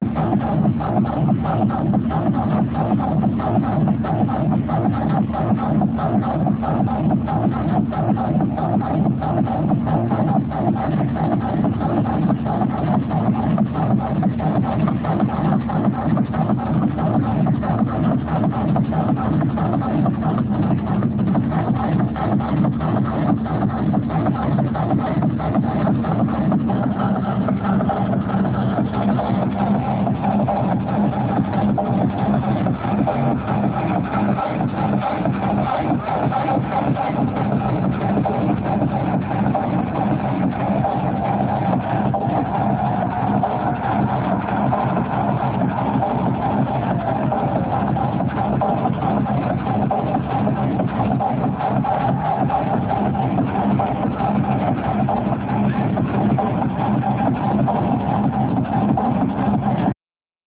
Here 2 videos of the teknival in realvideo